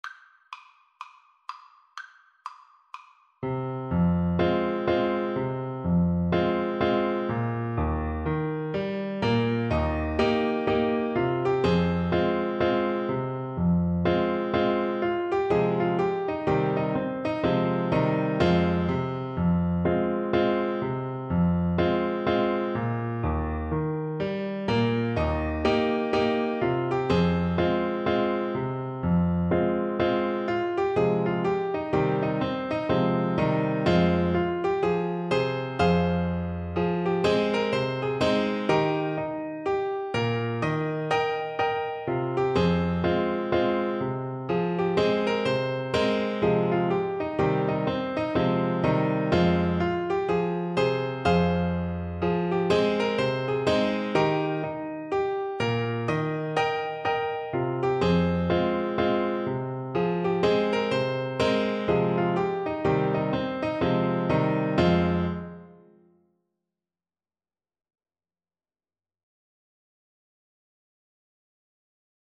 Clarinet
Traditional Music of unknown author.
Eb major (Sounding Pitch) F major (Clarinet in Bb) (View more Eb major Music for Clarinet )
Presto =c.180 (View more music marked Presto)
4/4 (View more 4/4 Music)
Eb5-G6